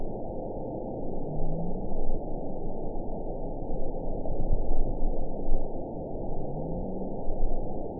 event 910148 date 01/16/22 time 06:36:55 GMT (3 years, 3 months ago) score 8.52 location TSS-AB07 detected by nrw target species NRW annotations +NRW Spectrogram: Frequency (kHz) vs. Time (s) audio not available .wav